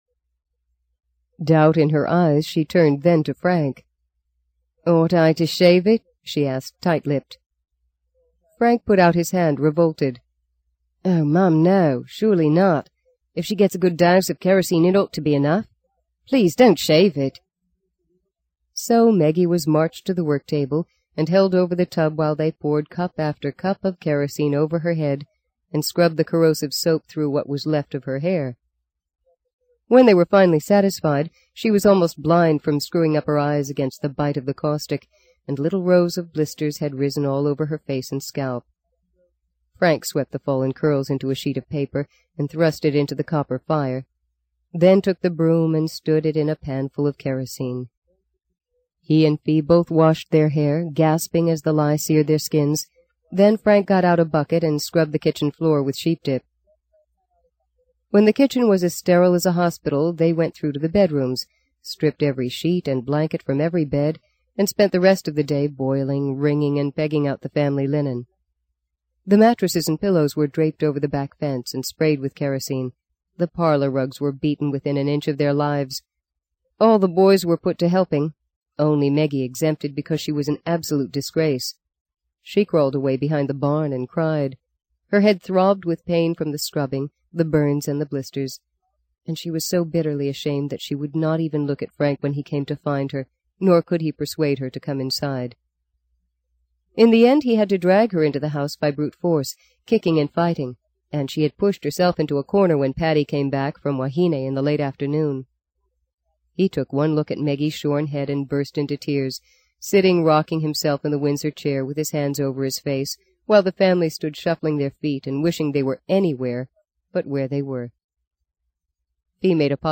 在线英语听力室【荆棘鸟】第二章 22的听力文件下载,荆棘鸟—双语有声读物—听力教程—英语听力—在线英语听力室